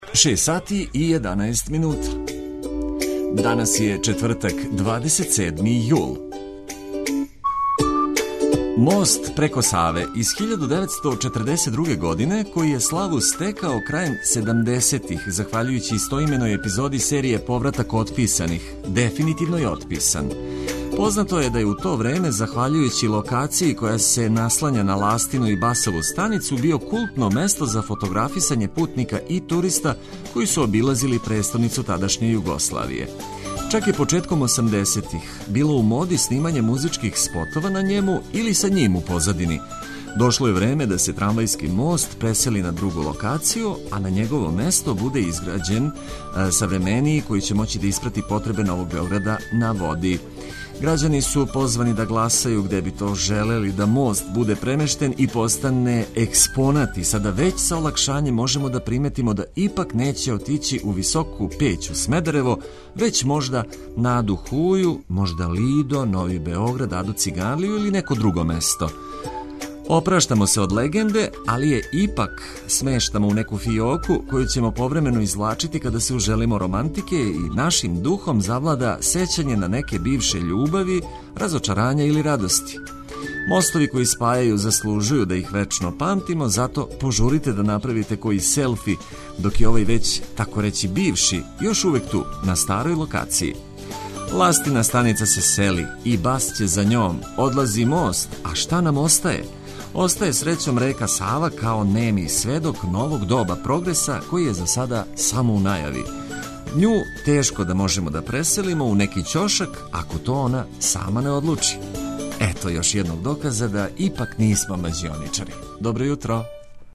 Ако сте отворили очи, покрените слух и ослушните шта смо припремили за лакши и успешнији почетак дана. Уз нас ћете сазнати све и лепо се забавити уз добру музику за лакше устајање из кревета.